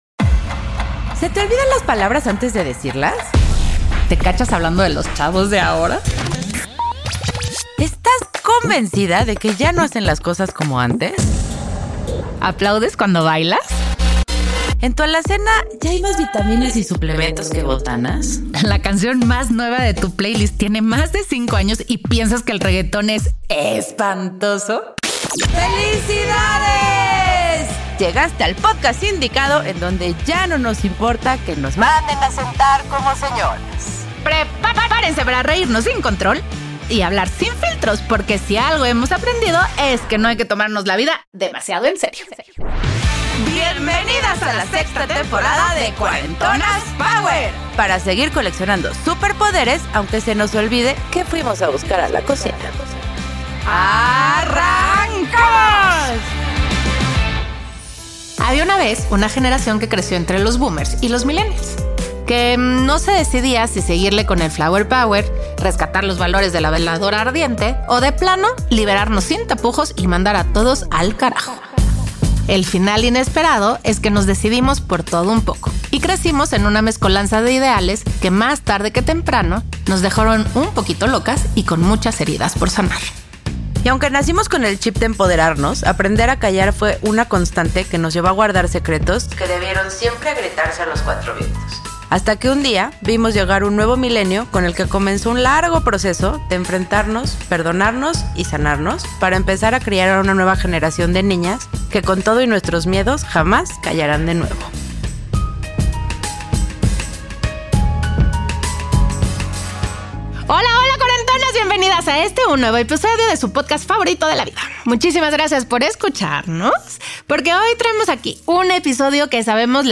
Dicen que los 40 son los nuevos 30, pero sabemos que no es cierto, los 40 se han reinventado y aquí dos cuarentonas están decididas a reivindicar el término para encontrar, si es que existe, la nueva definición. Prometemos risas y netas mientras descubrimos el verdadero Cuarentonas Power.¡Síganos en redes!